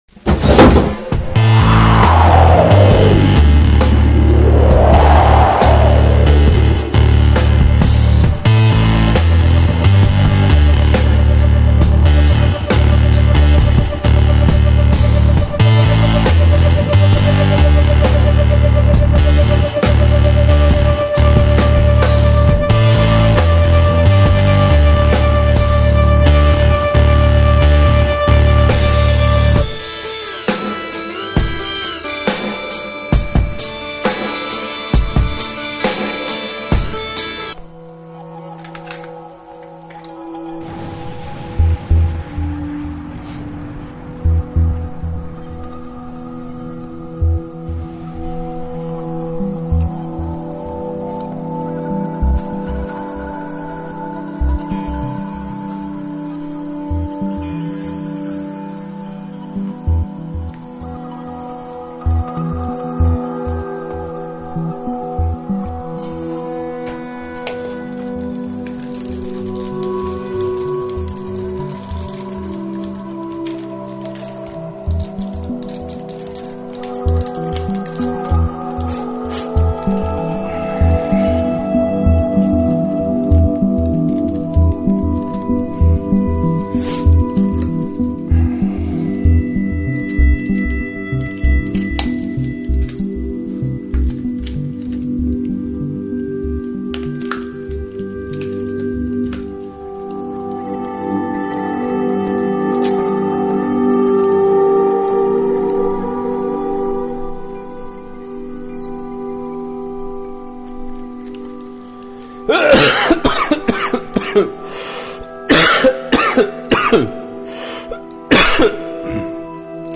Audycja jest transmitowana na żywo, dając możliwość oglądającym interaktywnego uczestniczenia w programie. 13 stycznia 2014 roku (100 dni przed transmisją pierwszego nagrania) rozpoczęła się „Wojna Światów”.